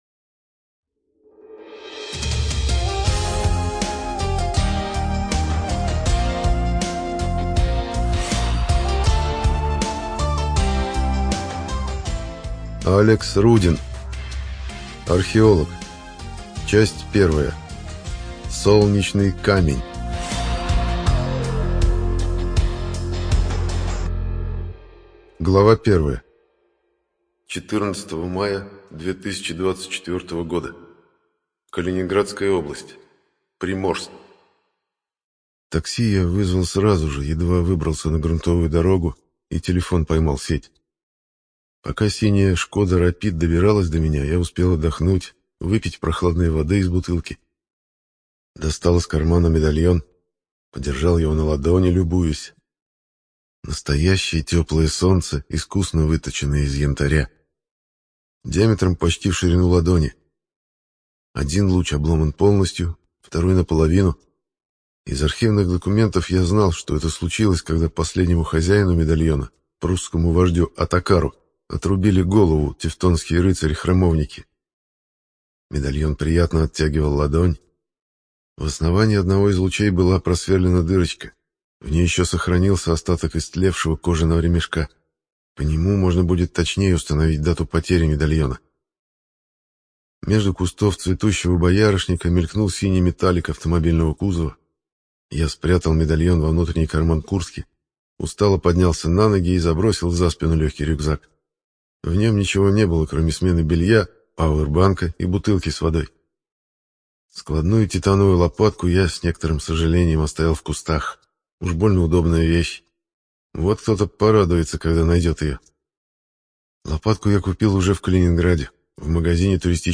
ЖанрАльтернативная история